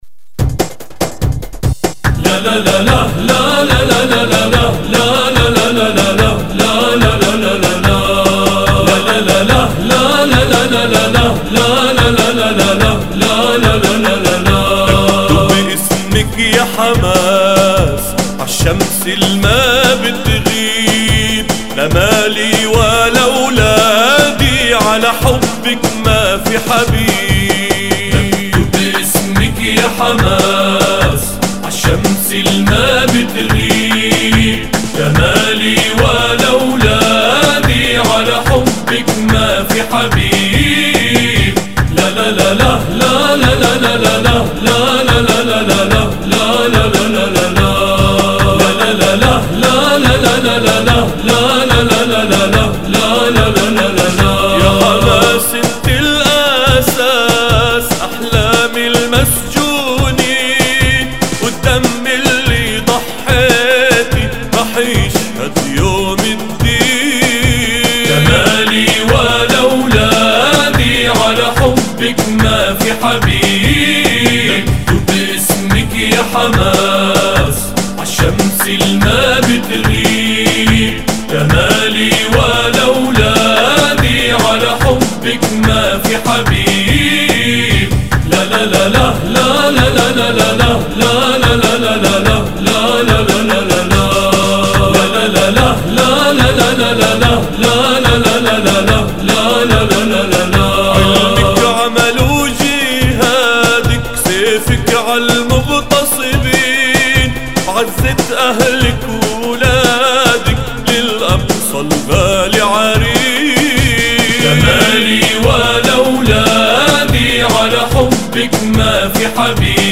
أناشيد فلسطينية